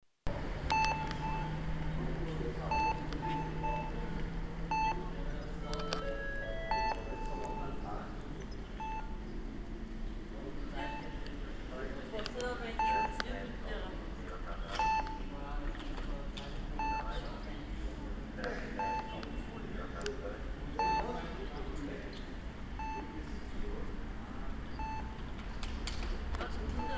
Hörbeispiel DISA als Auffindungssignal bei der Haltestelle:
DISA_Auffindungsignal.MP3